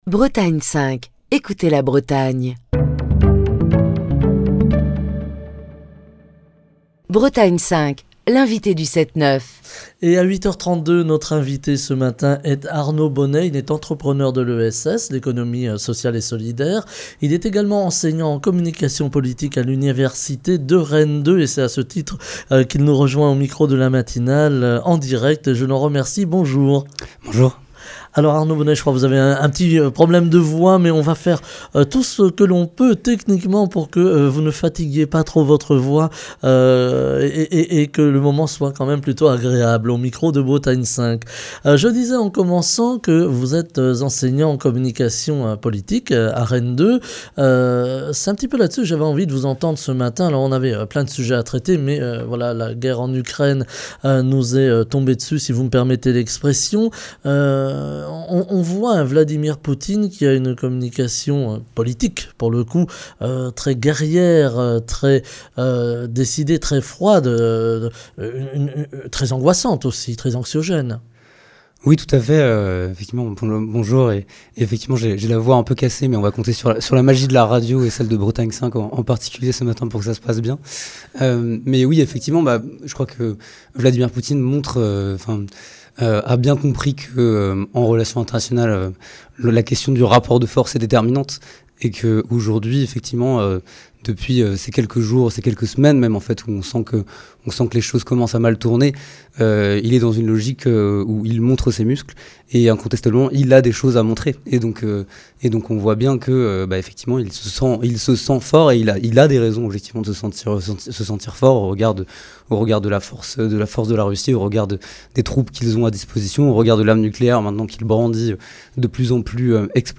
Bretagne 5 Matin. Écouter Télécharger Partager le podcast Facebook Twitter Linkedin Mail L'invité de Bretagne 5 Matin